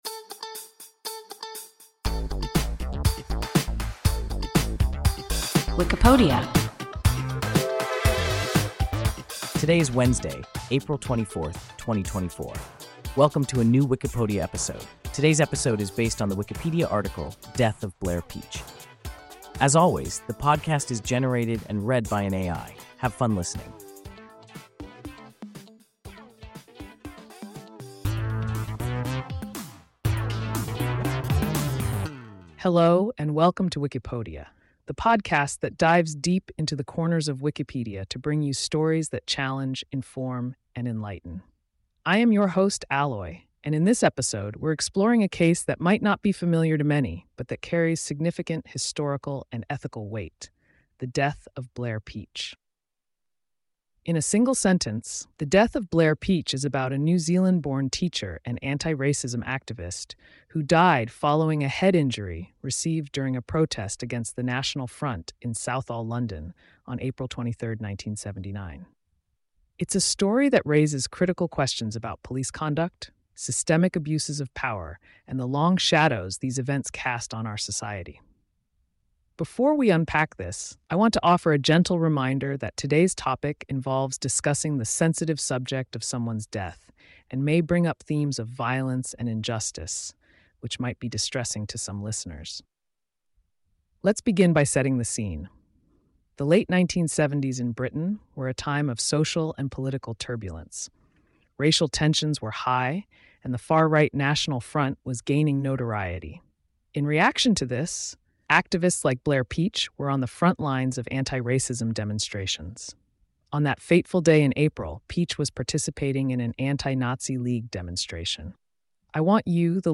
Death of Blair Peach – WIKIPODIA – ein KI Podcast